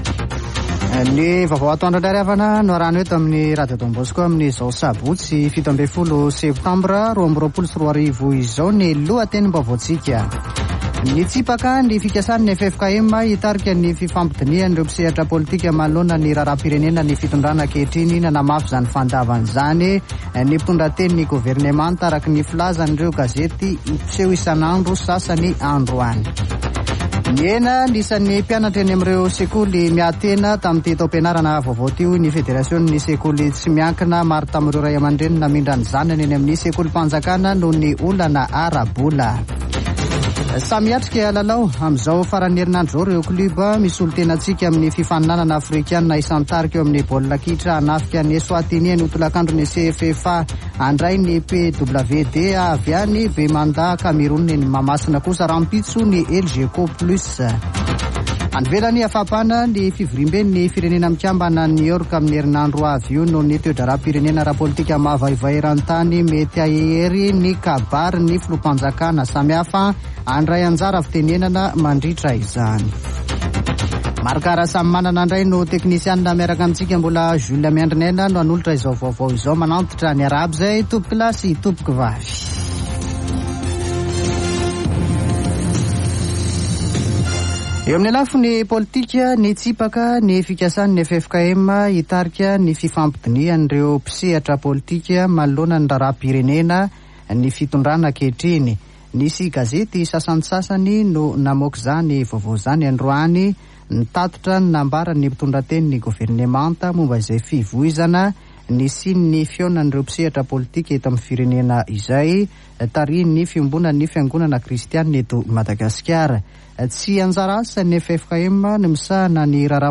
[Vaovao antoandro] Sabotsy 17 septambra 2022